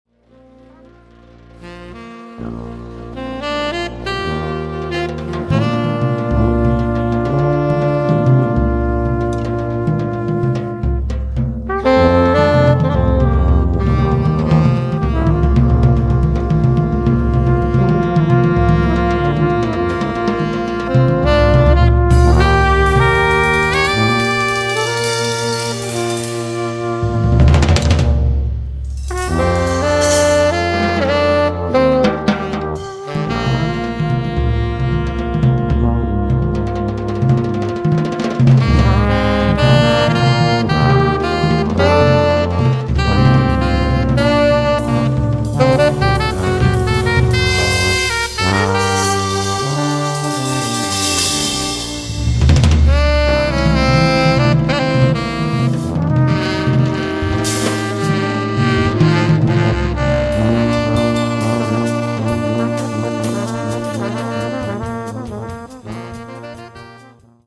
Recorded live in Vancouver, Canada, on february 25 2007
sax tenore
tromba e slide trumpet
tuba
batteria